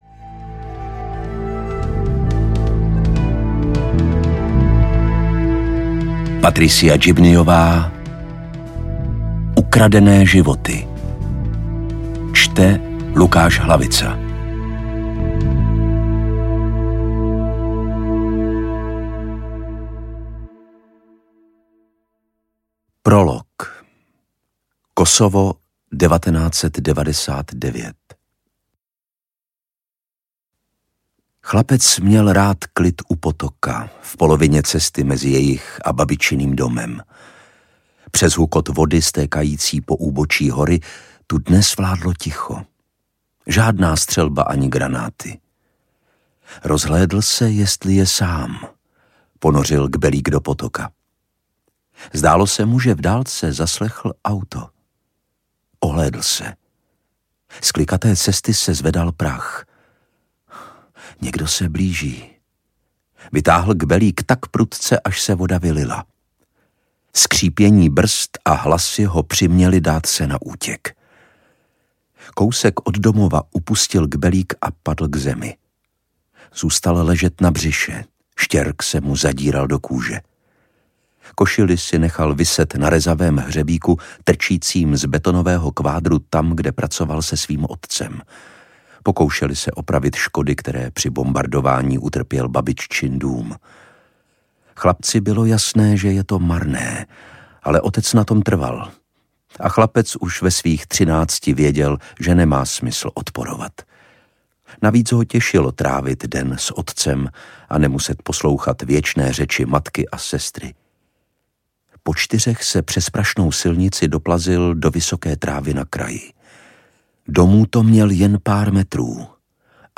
Ukradené životy audiokniha
Ukázka z knihy
• InterpretLukáš Hlavica